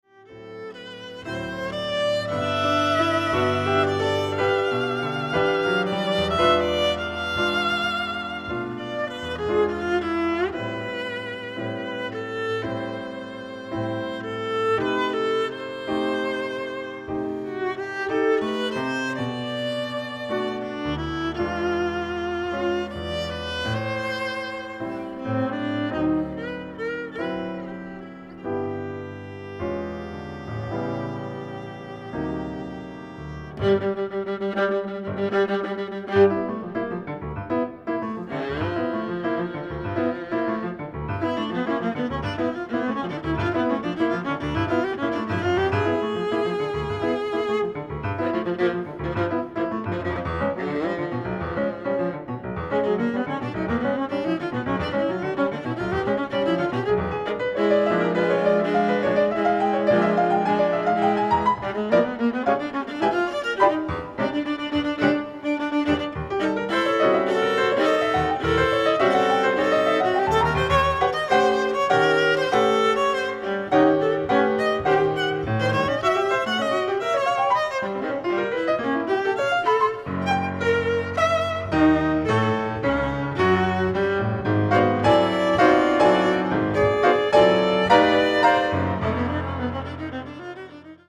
Violin and Piano